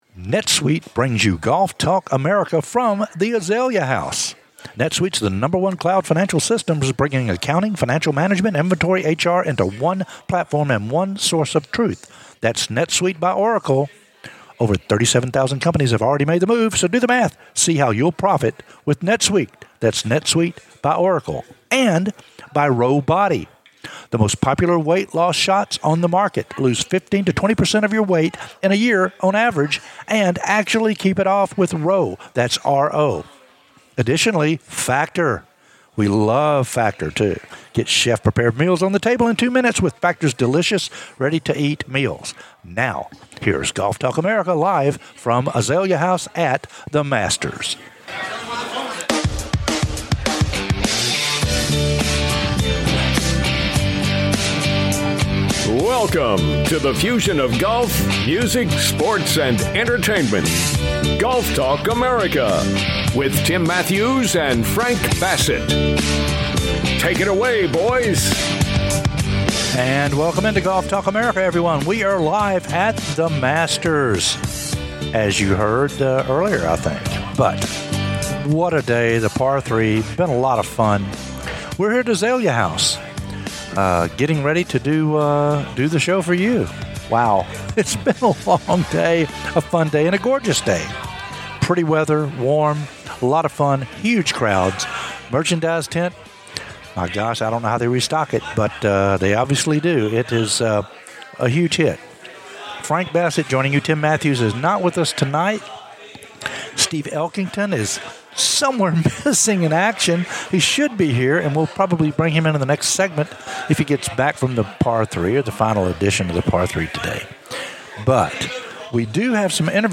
Interviews with Scottie, Brooks & Jordan from "THE MASTERS"